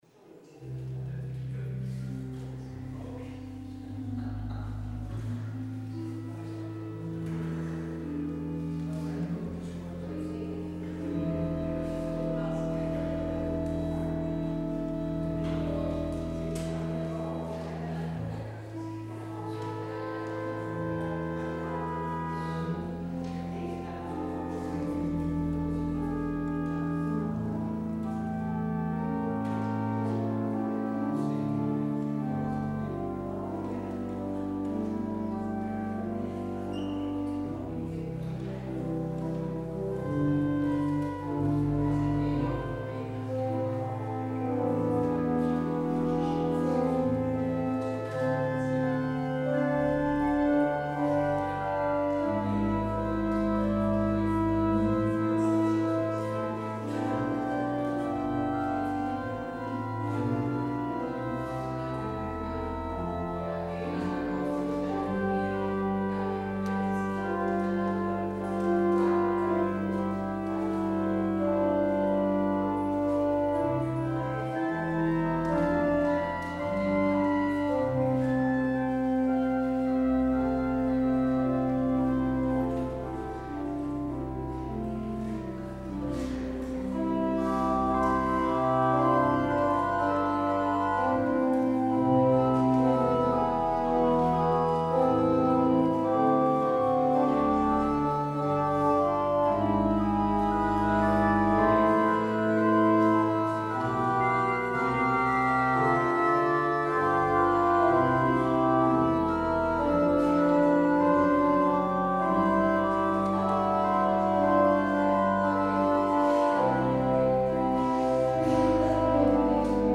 Het openingslied is Lied 440: 1 en 2 Ga stillen. Als slotlied hoort u: Lied 438: 1 en 2 God lof!